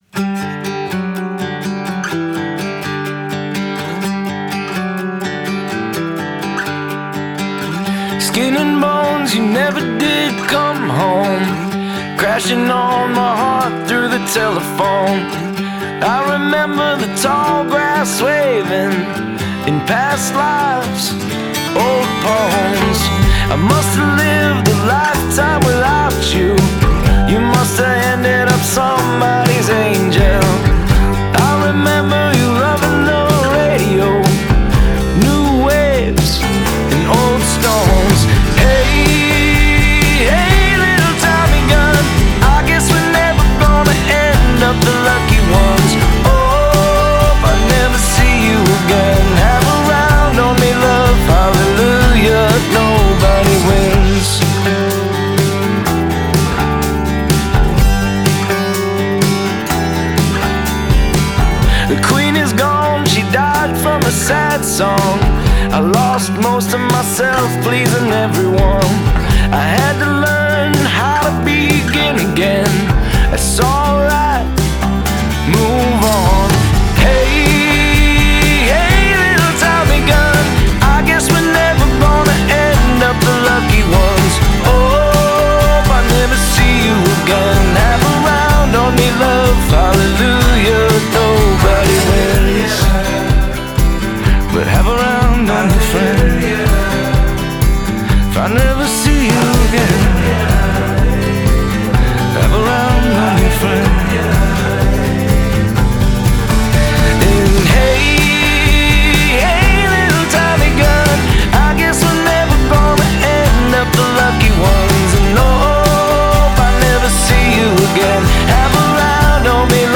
I love the guitar sounds on this record.